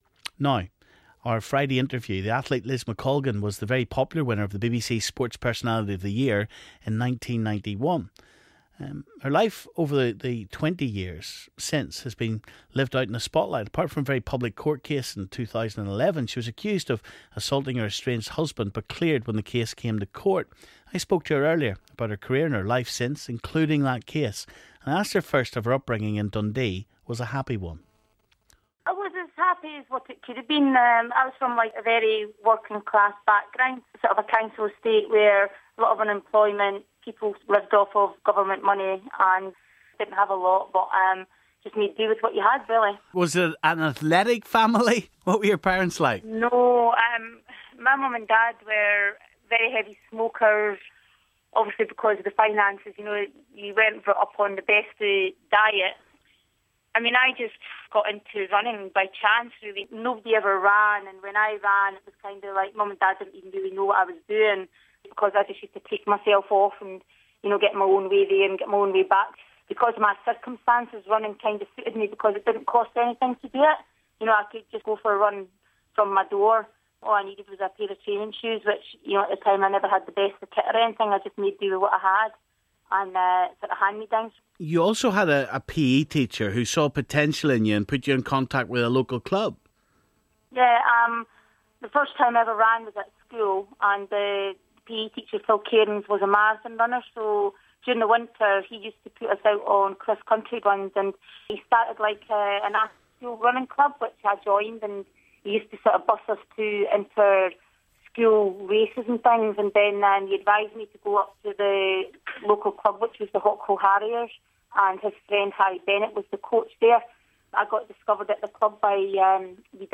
Today's 'Big Friday Interview' is with athlete Liz McColgan